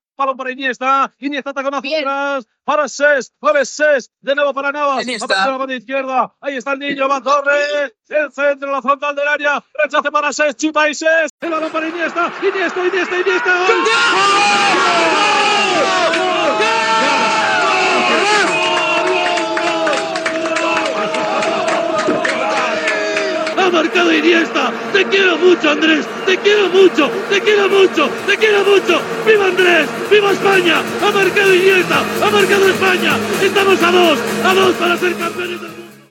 Transmissió del partit de la final de la Copa del Món de Futbol masculí 2010, des de Sud-àfrica.
Narració de la jugada del gol d'Andrés Iniesta a la pròrroga de la final del Campionat del Món de Futbol masculí de Sud-àfrica.
Esportiu